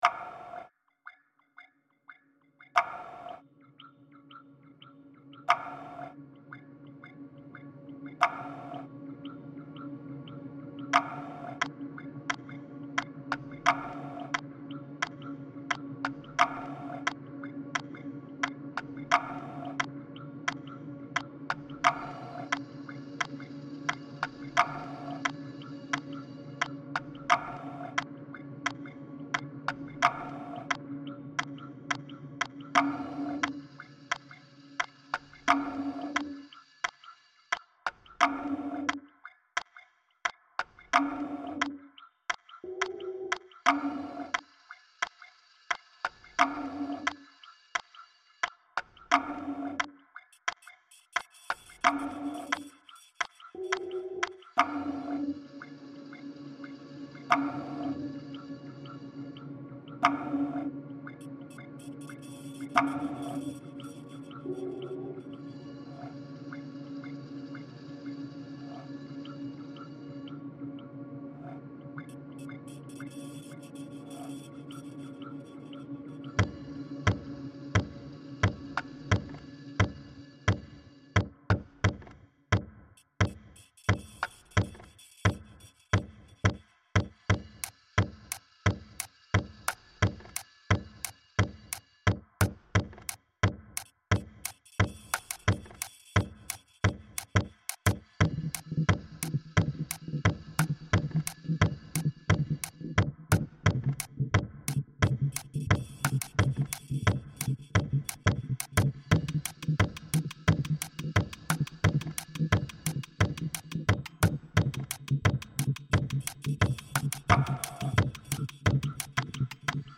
Composition